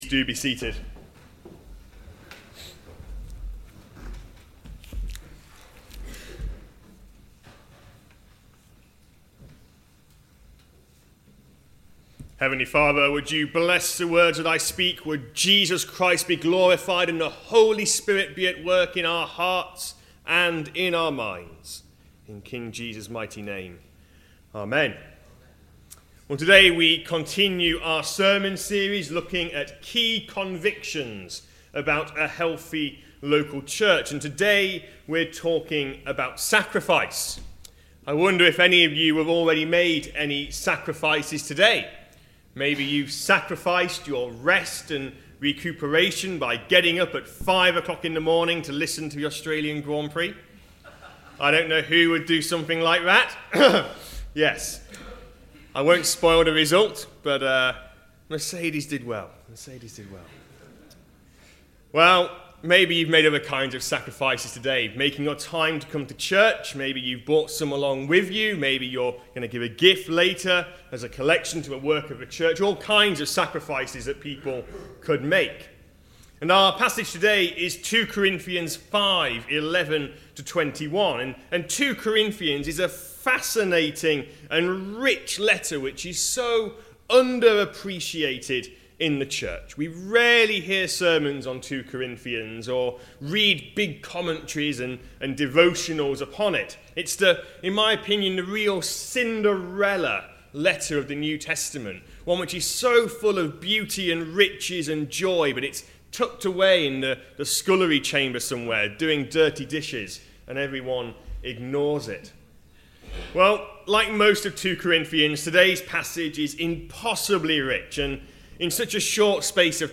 From our sermon series